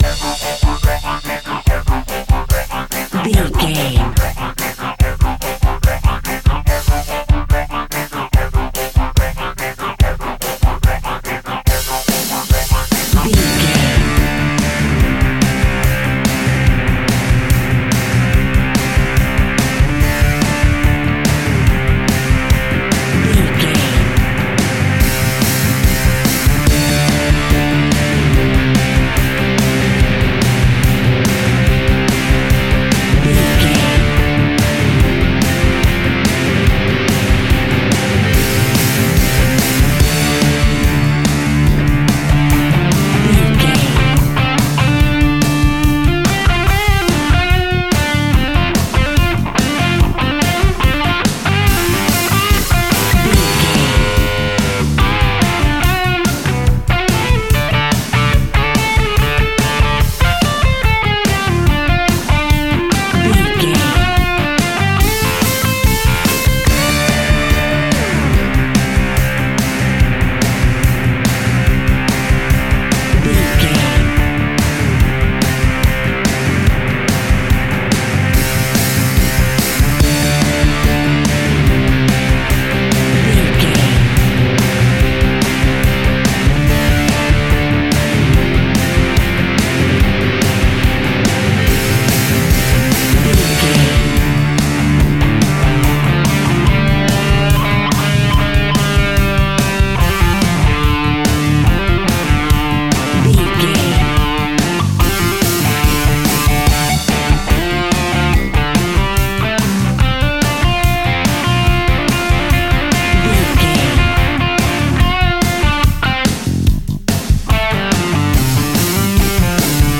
Epic / Action
Fast paced
Mixolydian
hard rock
blues rock
distortion
instrumentals
Rock Bass
heavy drums
distorted guitars
hammond organ